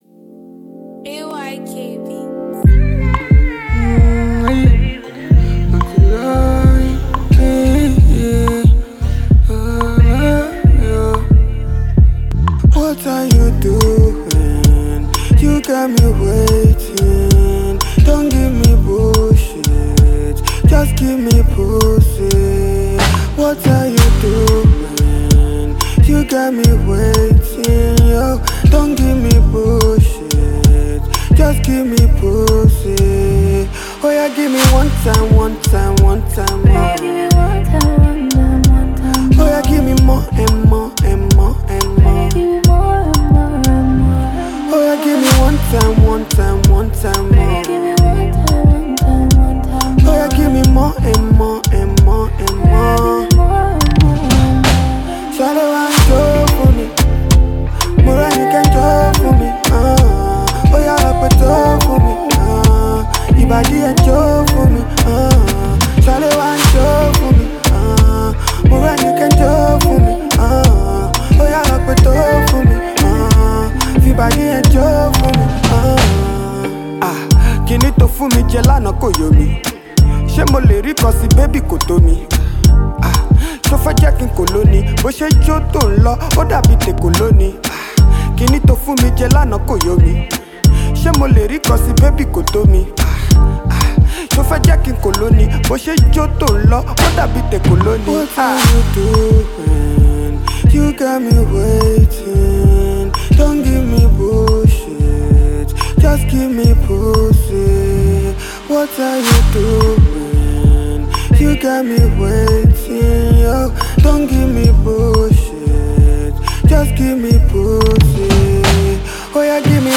Indigenous Rapper